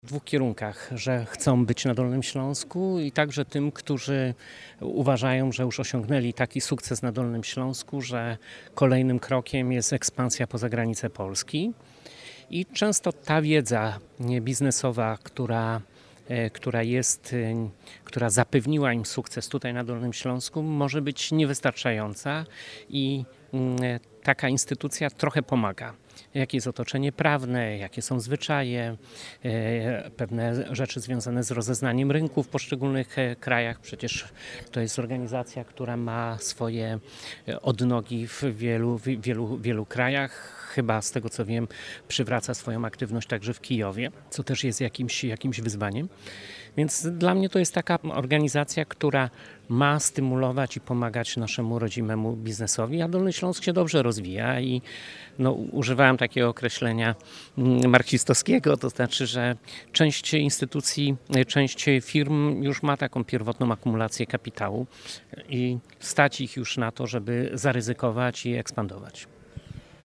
– To jest instytucja pomocnicza, która ma wesprzeć inwestorów – podkreślił Jarosław Obremski, wojewoda Dolnośląski.